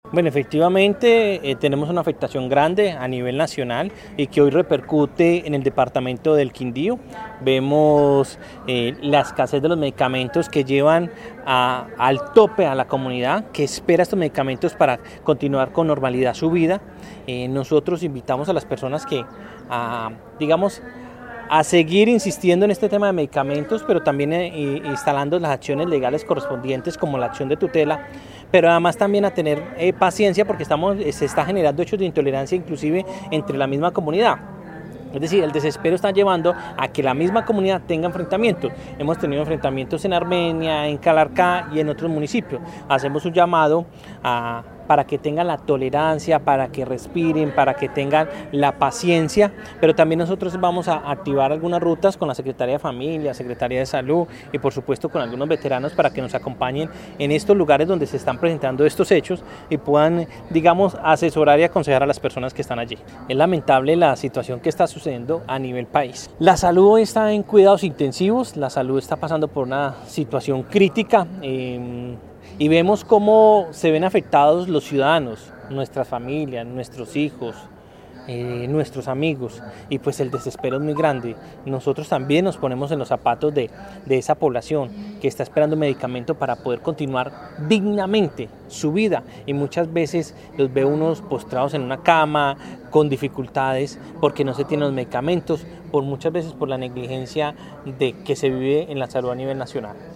Jaime Andrés Peréz, secretario del Interior del Quindío